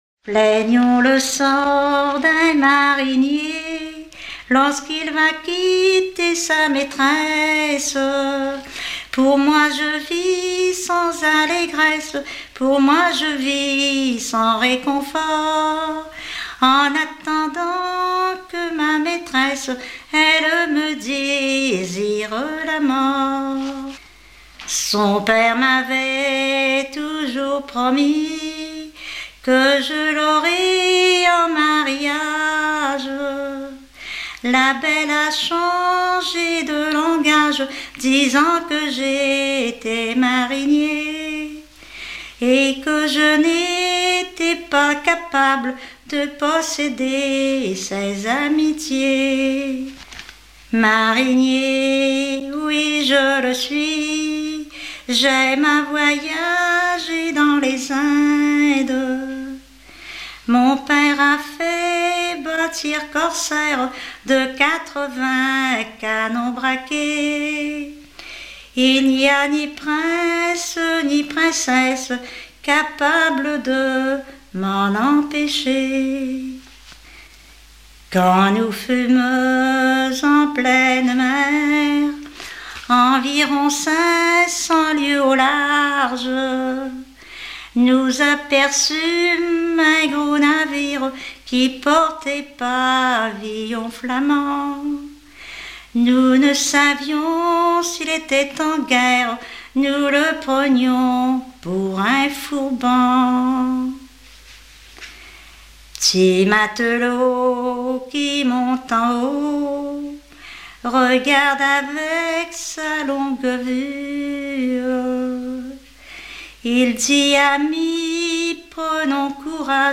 Localisation Saint-Révérend
Genre strophique
Pièce musicale éditée